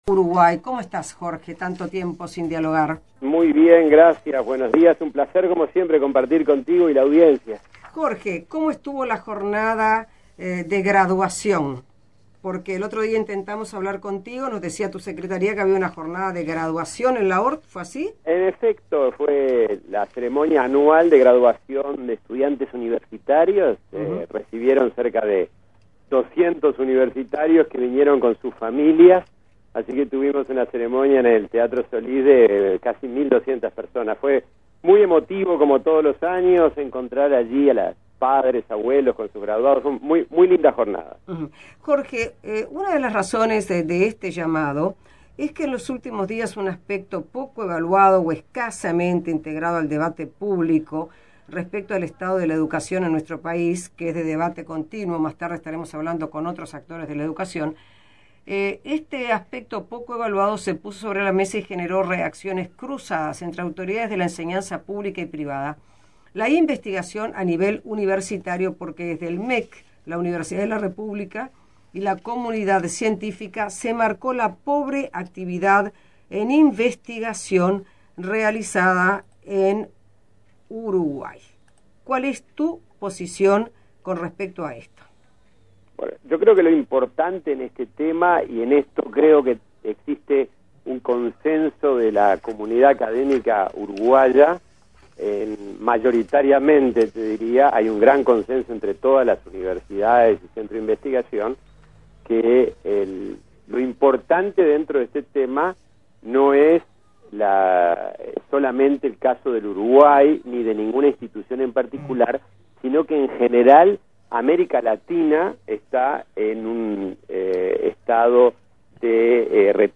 Entrevista en 1410 AM Libre